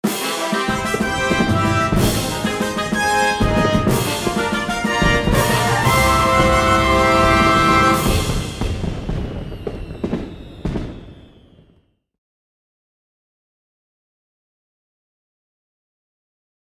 a dramatic, triumphant, slightly slower arrangement